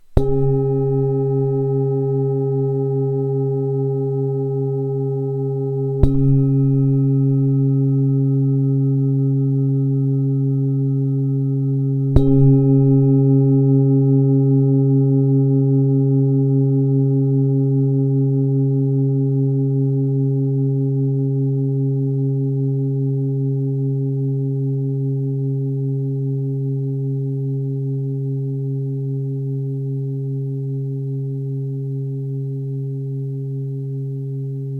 Zdobená tibetská mísa C3 25,5cm
Nahrávka mísy úderovou paličkou:
Jde o ručně tepanou tibetskou zpívající mísu dovezenou z Nepálu.